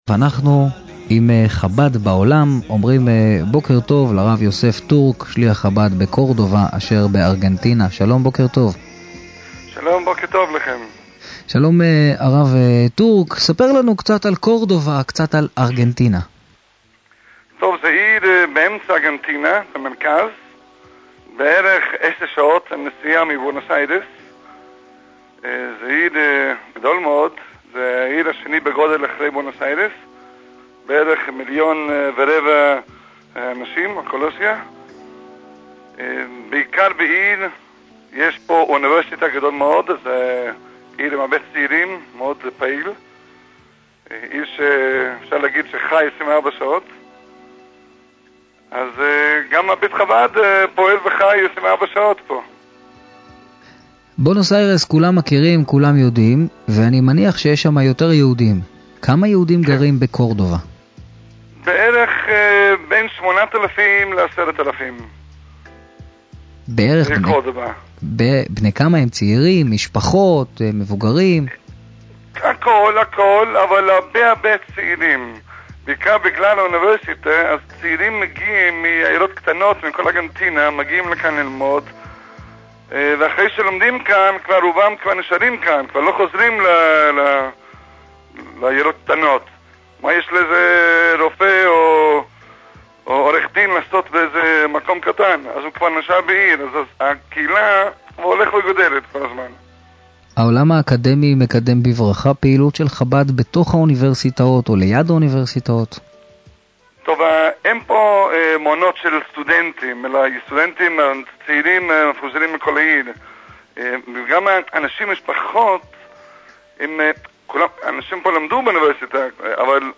ברדיו "קול חי".